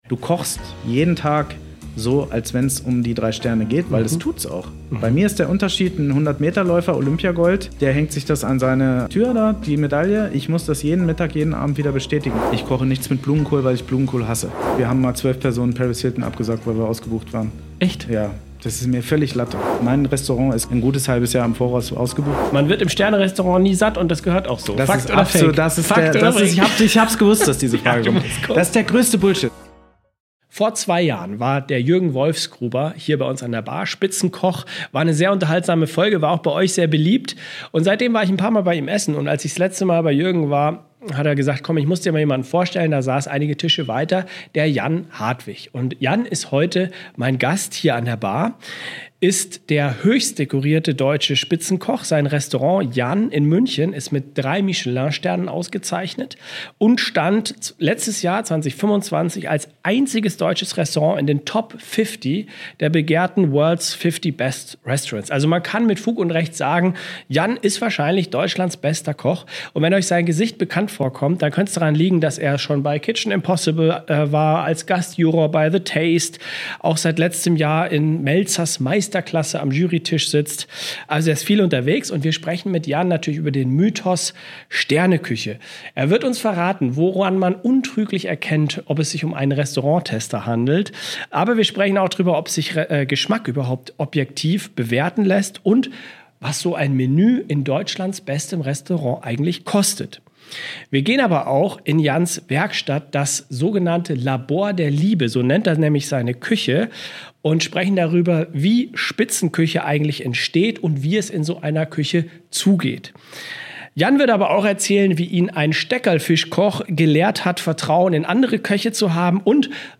Heute sitzt Deutschlands wohl bester Koch, Jan Hartwig, bei uns an der Sundowner-Bar und nimmt uns mit in die Welt der Spitzengastronomie.